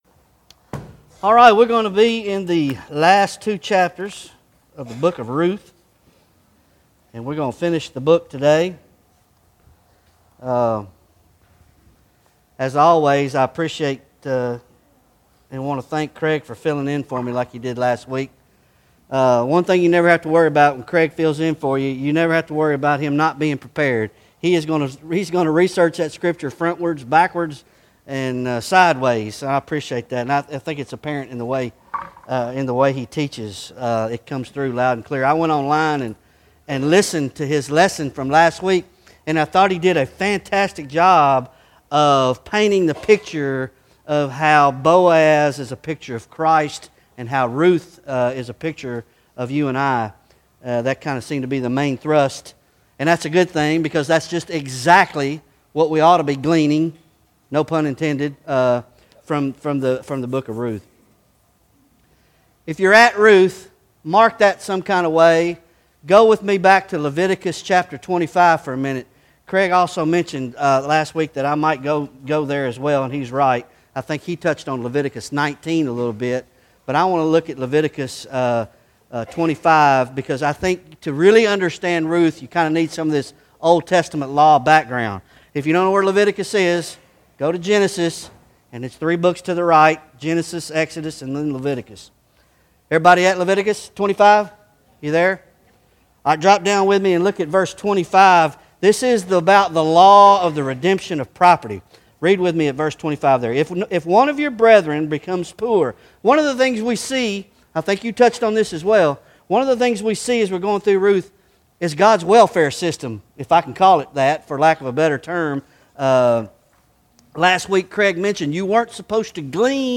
Bible Study Ruth Ch 1